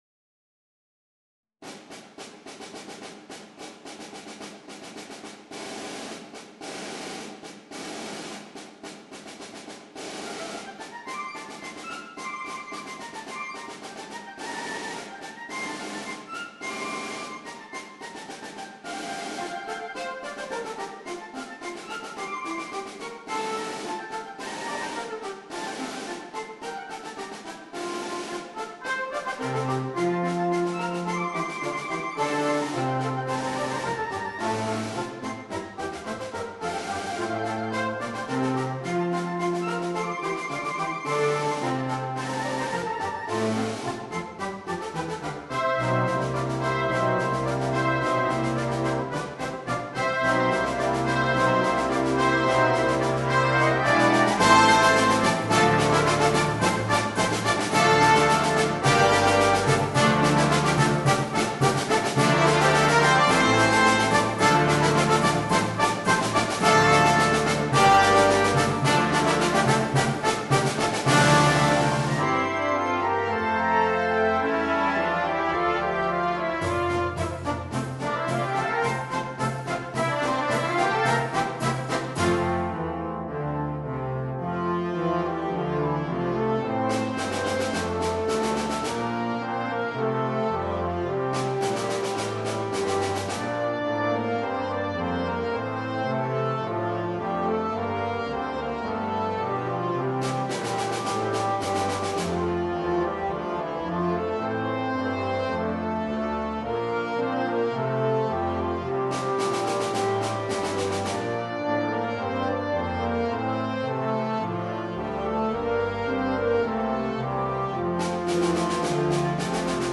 MUSICA PER BANDA